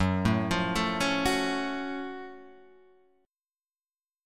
F# Minor Major 7th